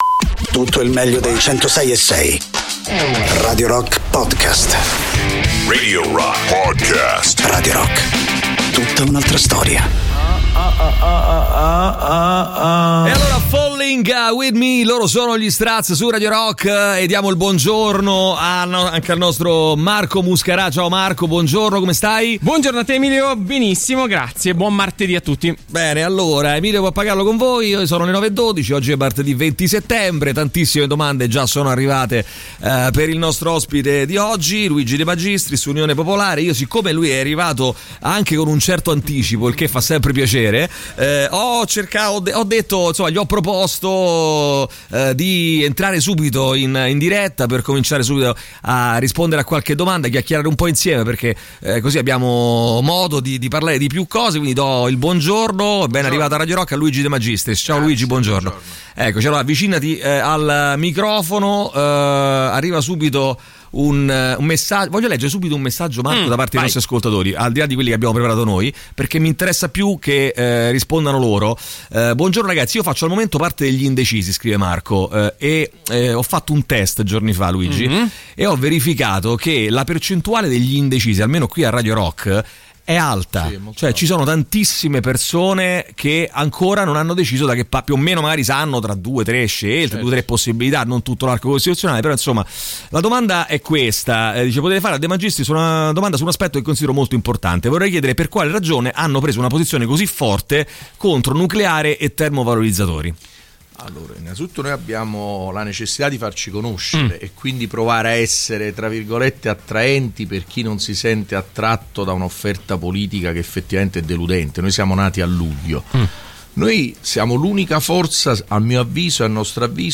Luigi de Magistris, ex sindaco di Napoli e portavoce di Unione Popolare, ospite in studio